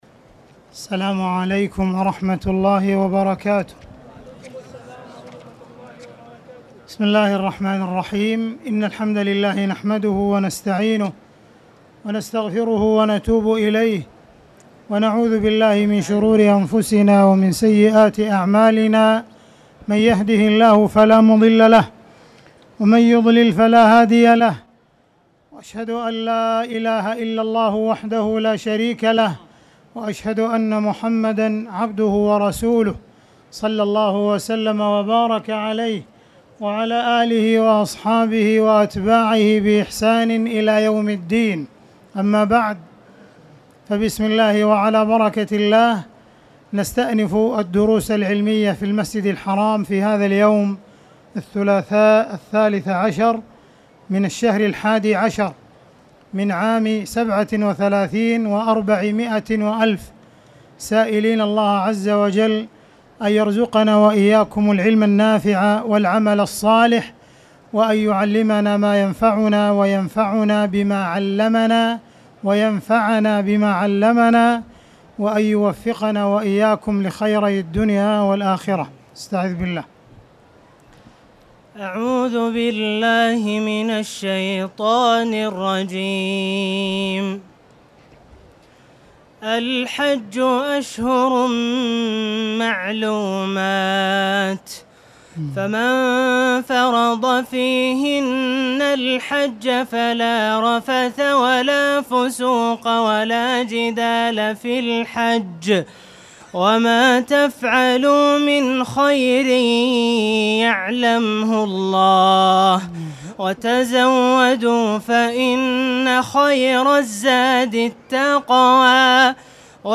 تاريخ النشر ١١ ذو القعدة ١٤٣٧ هـ المكان: المسجد الحرام الشيخ